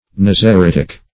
Nazaritic \Naz`a*rit"ic\, prop. a. Of or pertaining to a Nazarite, or to Nazarites.